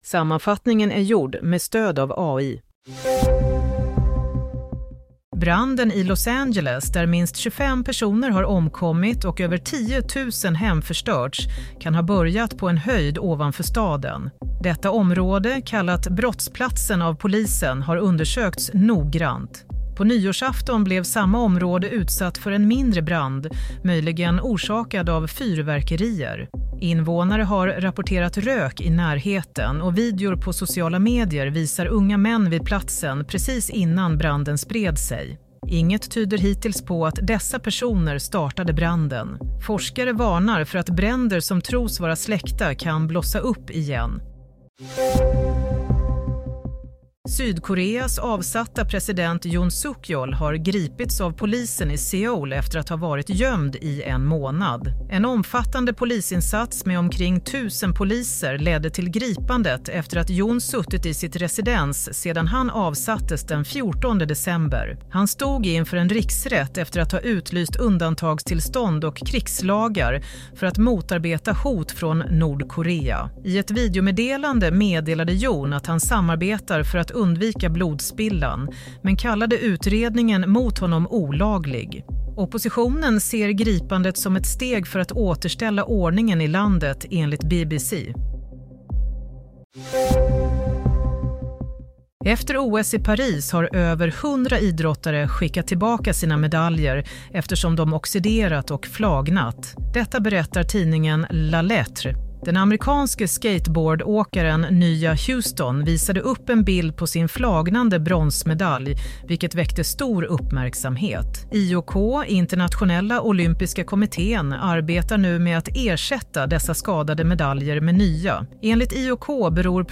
Play - Nyhetssammanfattning – 15 januari 07:00